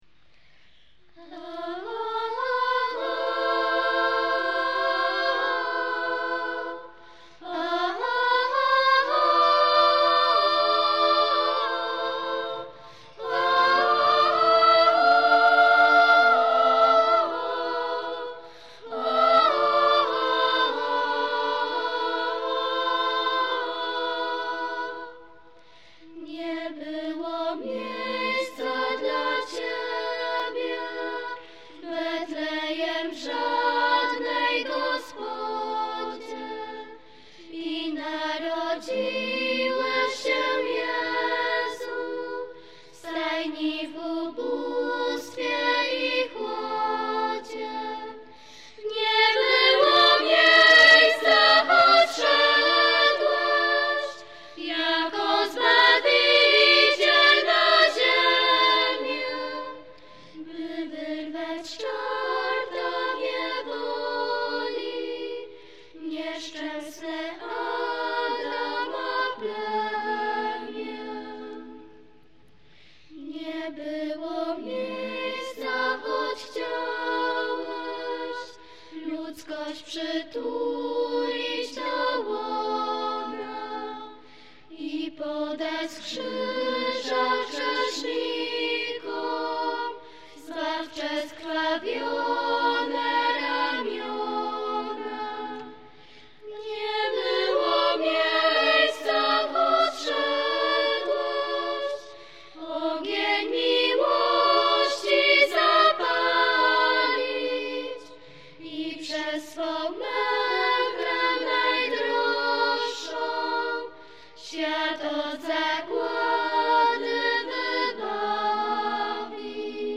Nagrania live, utwory w formacie mp3 (96kbps),
zarejestrowane na koncertach w Jasieniu i w Domecku.